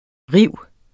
Udtale [ ˈʁiwˀ ]